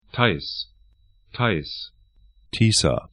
Theiss [CH], Theiß [AT, DE] tais Tysa 'ti:sa uk Fluss / stream 48°05'N, 22°52'E